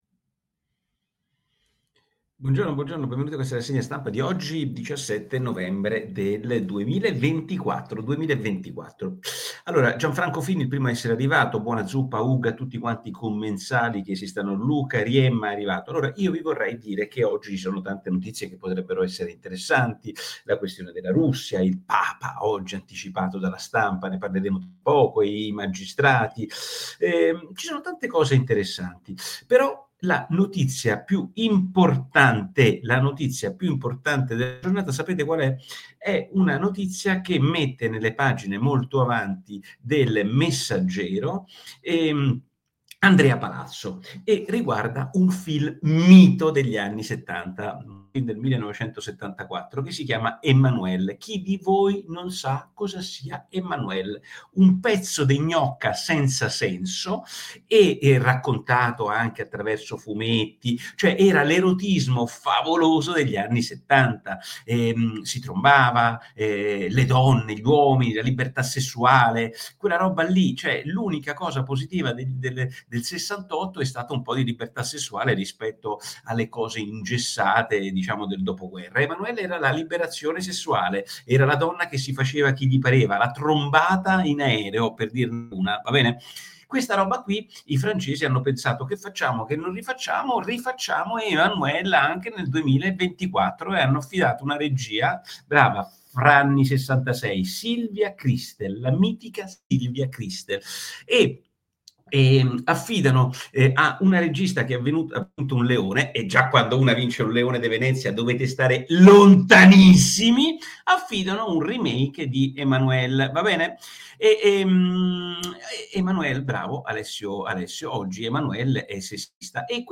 Il podcast ufficiale di Nicola Porro, che tutti i giorni cucina una rassegna stampa per i suoi ascoltatori.